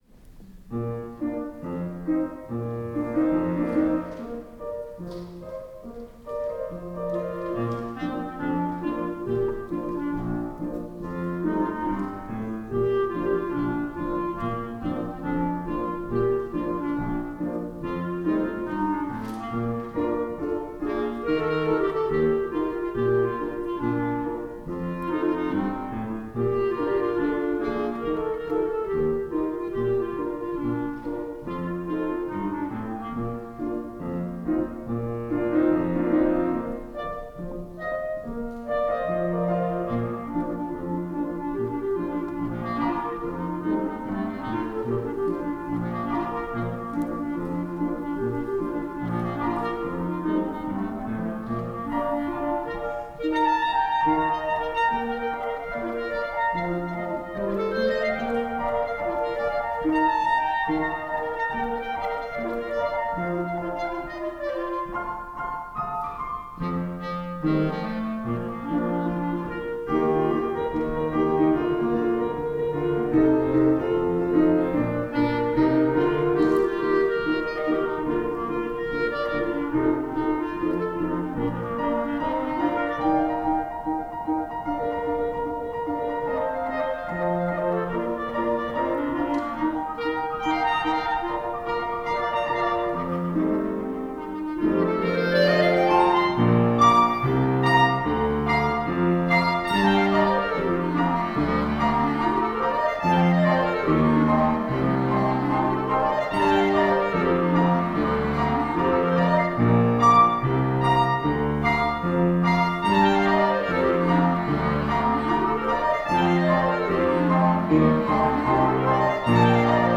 for Clarinet and Piano (2005)